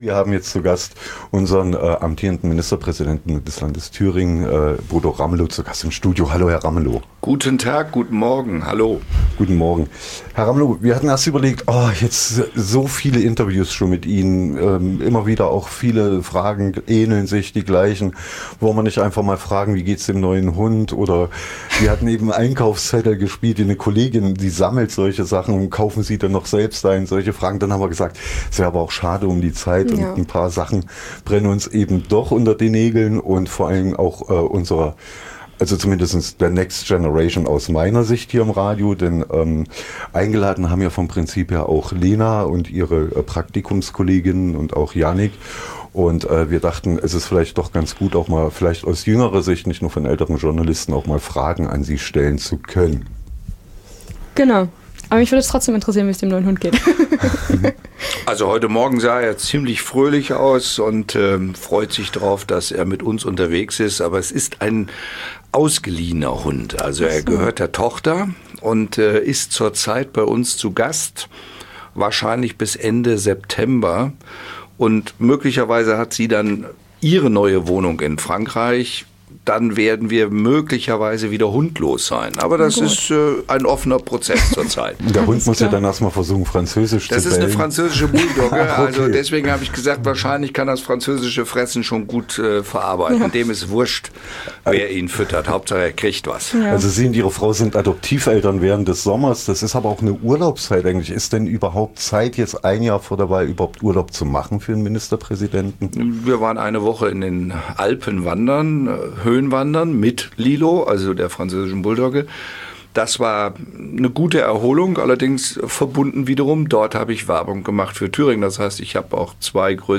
Unter 6 Augen - ein Gespräch mit Ministerpräsident Bodo Ramelow
INTERVIEW Bodo Ramelow.mp3